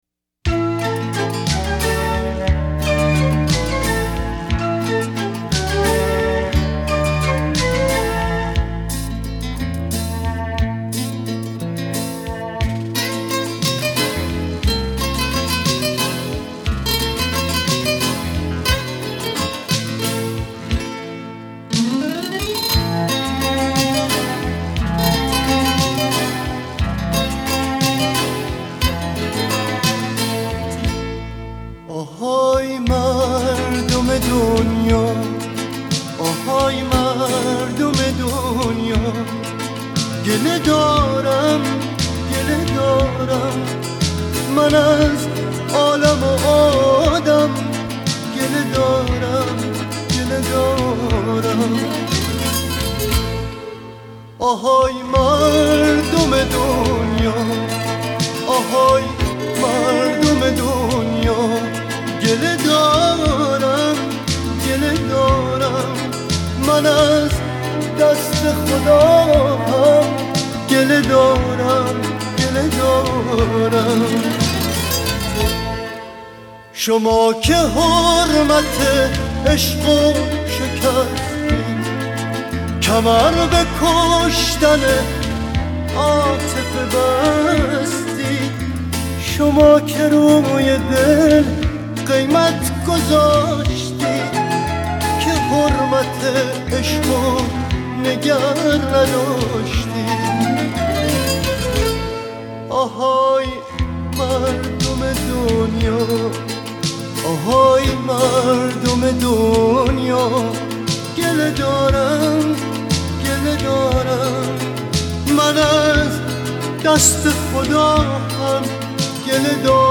جنس صدای وی باس است.